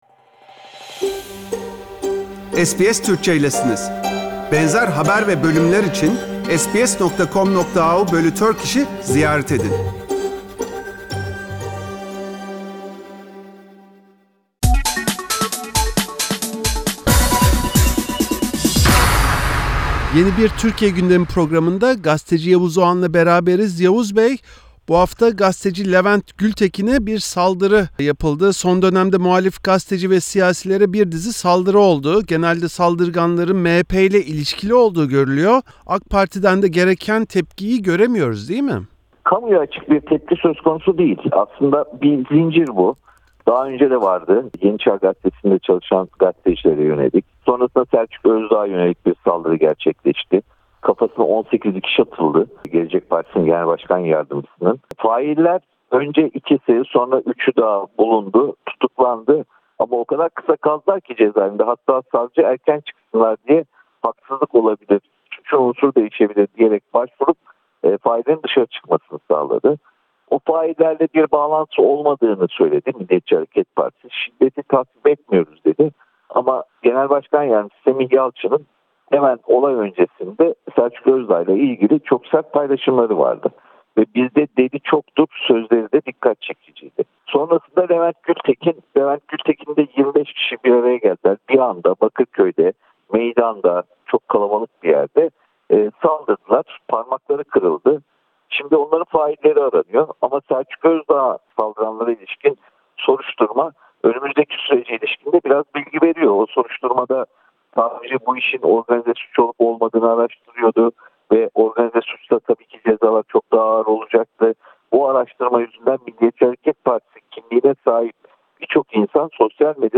SBS Türkçe için gündemi değerlendiren gazeteci Yavuz Oğhan, Dünya Kadınlar Günü’nün kutlandığı bir haftada gündemde tartışmaların merkezinde şiddet olduğunu söyledi.